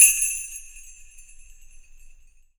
SLEIGHBELL-1.wav